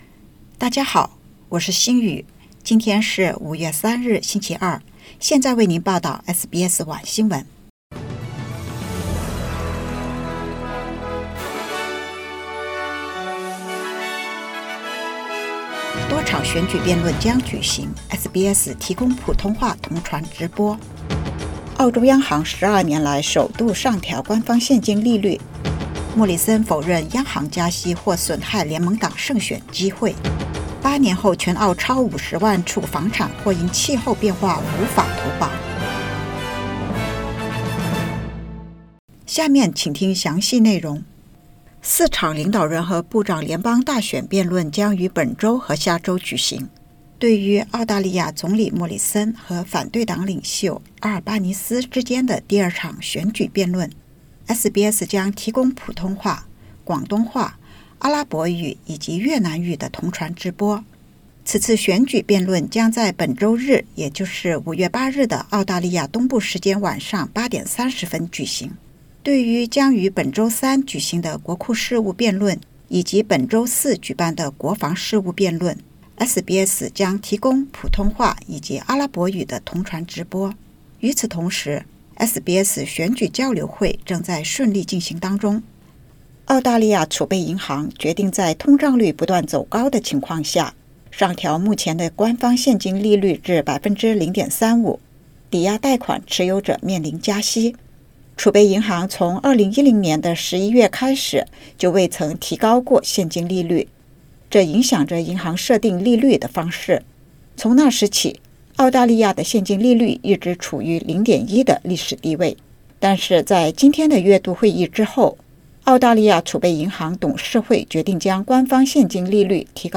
SBS晚新闻（2022年5月3日）
SBS Mandarin evening news Source: Getty Images